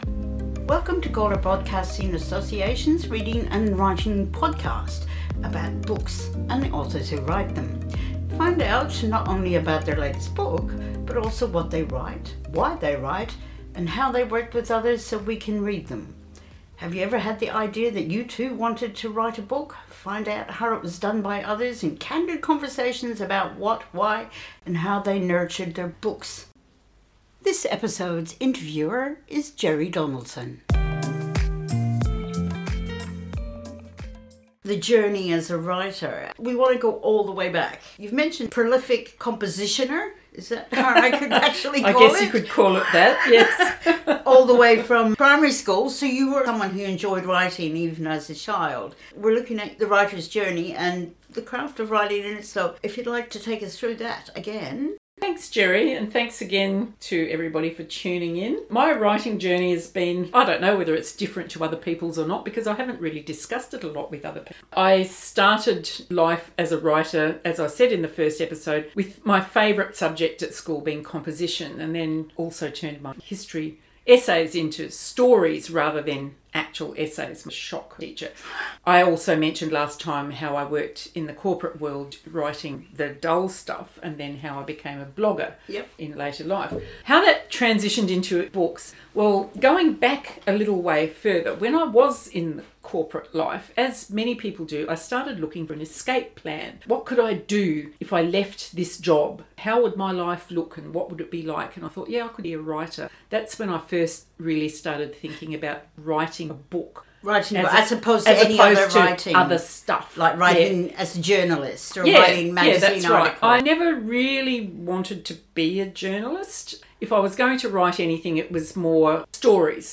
We have the second interview of our series of three